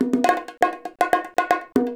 130BONGO 17.wav